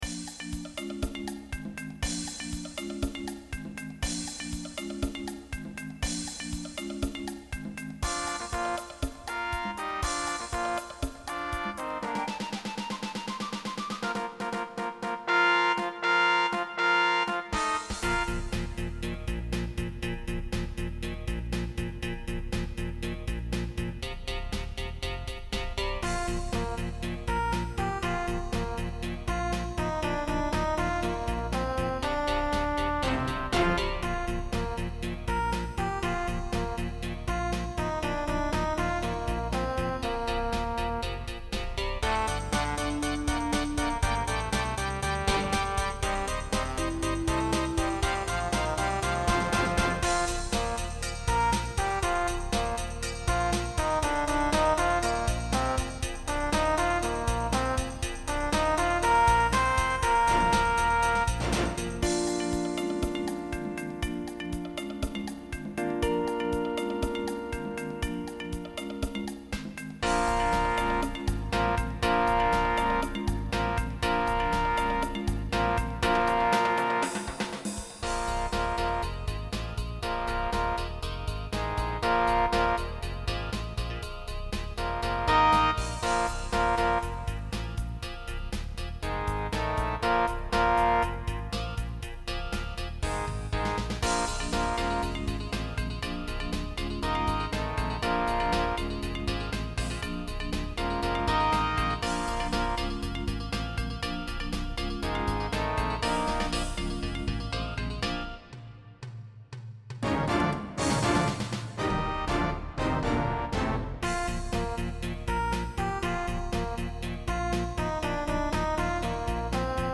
BRASS QUINTET
QUINTETTO - 3 trombe Bb - 2 tromboni c.b. • BASE MP3